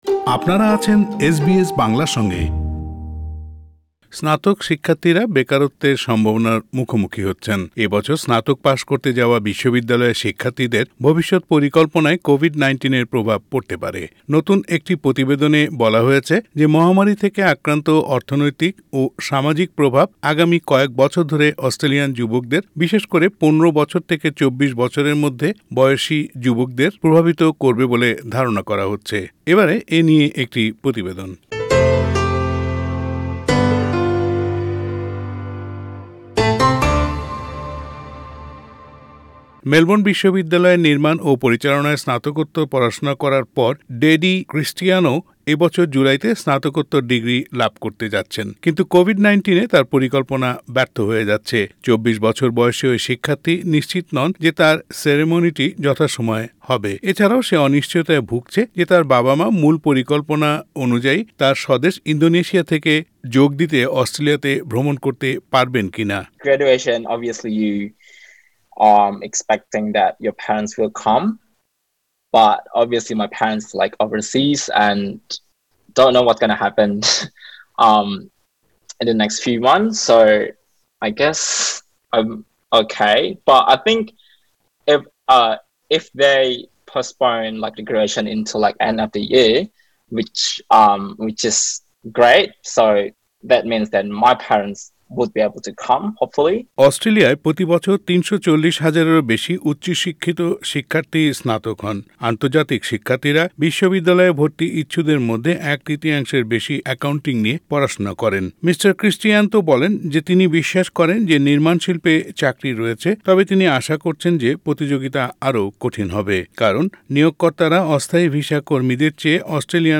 এই বছর স্নাতক পাস করতে যাওয়া বিশ্ববিদ্যালয়ের শিক্ষার্থীদের ভবিষৎ পরিকল্পনায় কোভিড -১৯ এর প্রভাব পড়তে পারে। নতুন একটি প্রতিবেদনে বলা হয়েছে যে মহামারী থেকে আক্রান্ত অর্থনৈতিক ও সামাজিক প্রভাব আগামী কয়েক বছর ধরে অস্ট্রেলিয়ান যুবকদের বিশেষ করে ১৫ বছর থেকে ২৪ বছরের মধ্যে প্রভাবিত করবে বলে ধারণা করা হচ্ছে। প্রতিবেনটি শুনতে উপরের অডিও লিংকটিতে ক্লিক করুন।